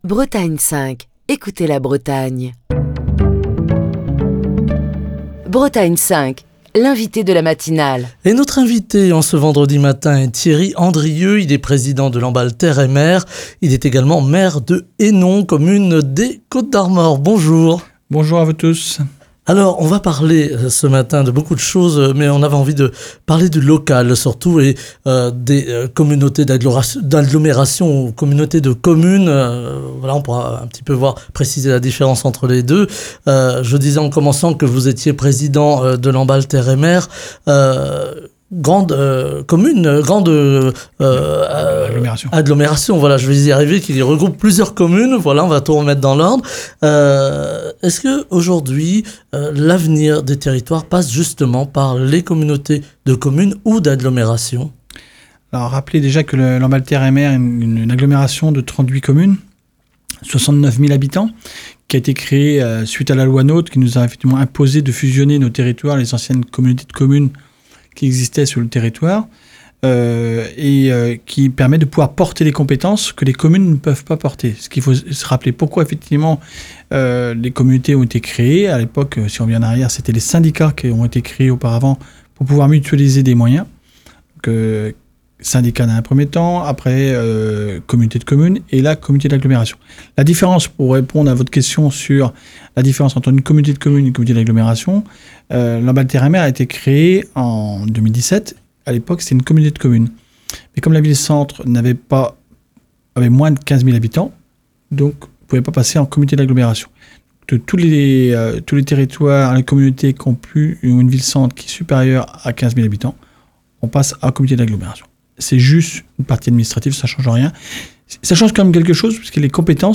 Thierry Andrieux, président de Lamballe Terre et Mer Agglomération et maire de Hénon, dans les Côtes-d’Armor, était l’invité politique de Bretagne 5 Matin. Au micro de Bretagne 5, Thierry Andrieux est longuement revenu sur le fonctionnement des communautés d’agglomération, s’appuyant sur son expérience à la tête de Lamballe Terre et Mer. Il a rappelé les nombreux avantages qu’ont pu retirer les communes grâce à l’intercommunalité et aux transferts de compétences opérés au profit des agglomérations.